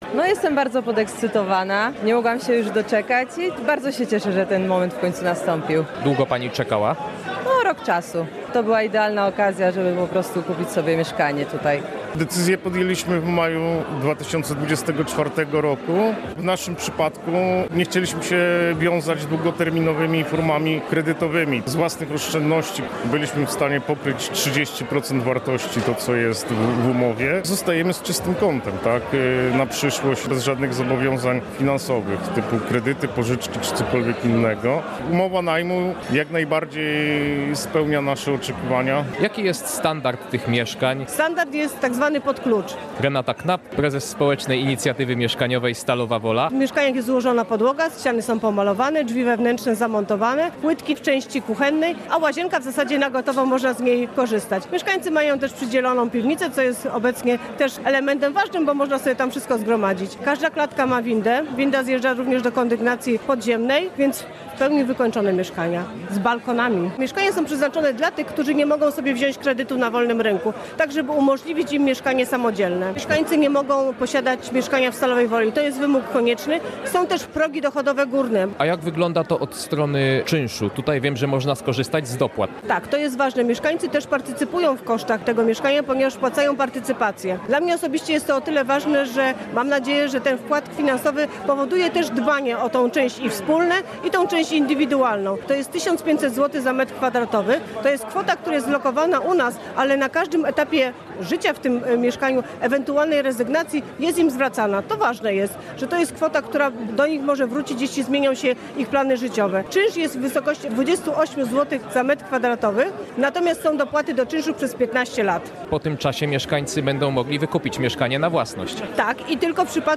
Relacje reporterskie • 104 mieszkania wybudowane w ramach programu Społecznej Inicjatywy Mieszkaniowej Stalowa Wola oddano do użytku na Osiedlu Ogrodowym.